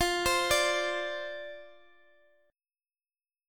Listen to F56 strummed